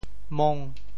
“摸”字用潮州话怎么说？
mong5.mp3